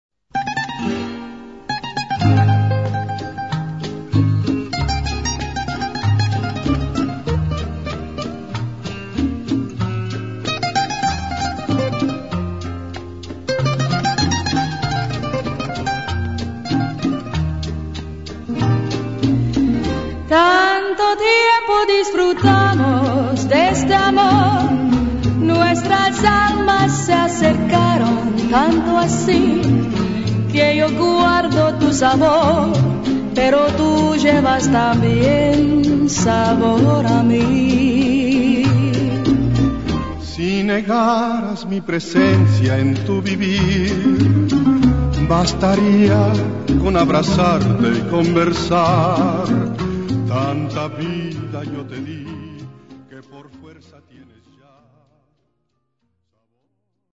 Baile lento de origen español.